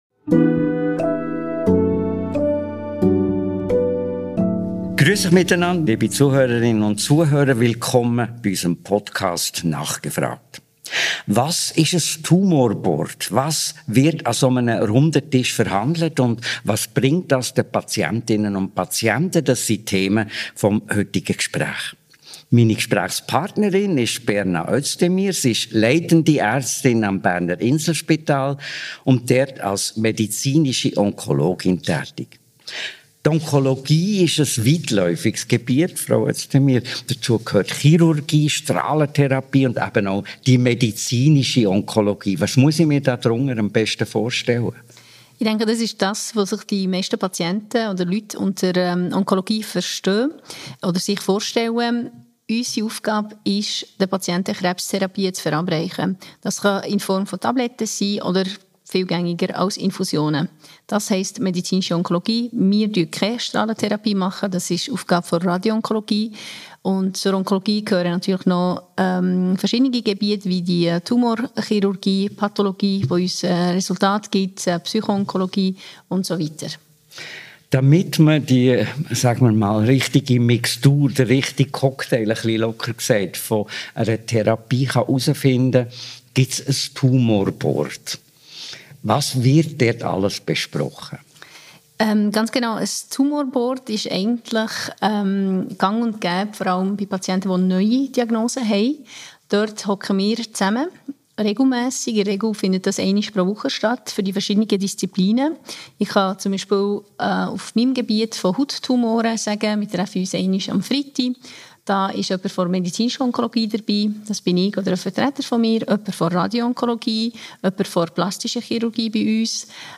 Ein Gespräch über Teamarbeit, personalisierte Behandlungen und die Realität von Gendermedizin in der Onkologie.